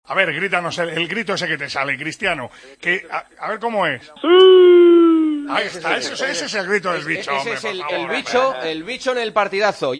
Cristiano Ronaldo hace su famoso grito con Manolo Lama y Juanma Castaño en El Partidazo de COPE